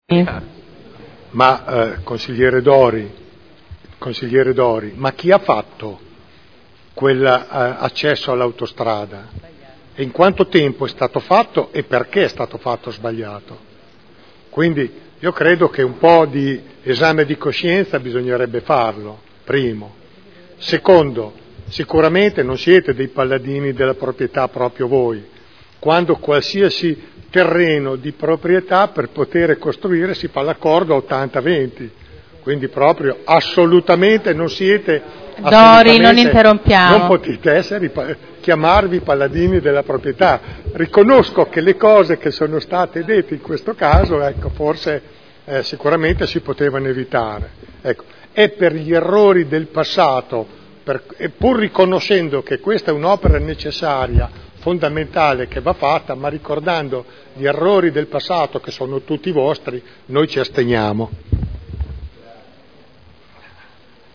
Dichiarazioni di voto
Audio Consiglio Comunale